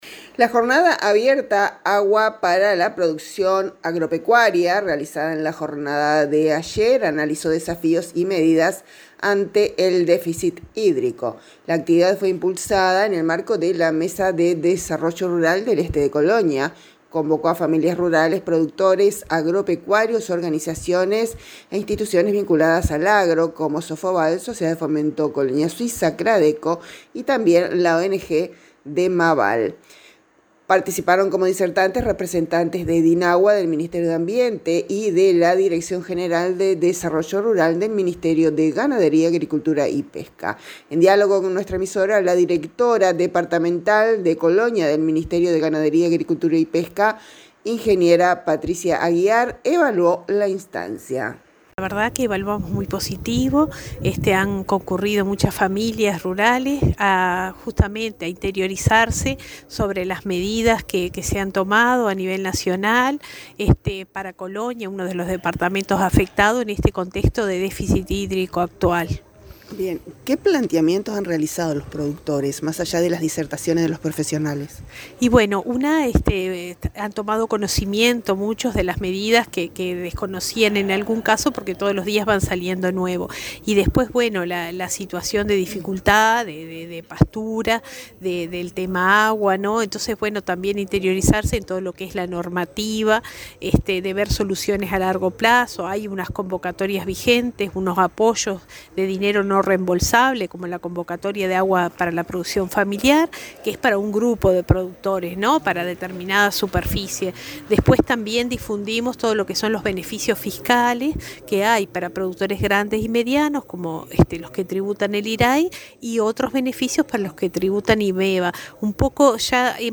En diálogo con nuestra emisora, la directora departamental del Ministerio de Ganadería, Agricultura y Pesca en Colonia, Patricia Aguiar, evaluó la instancia como “muy positiva”, destacando la amplia concurrencia y el interés de los productores por interiorizarse sobre las medidas vigentes ante el actual déficit hídrico.
Escuchamos a la Directora del MGAP Patricia Aguiar.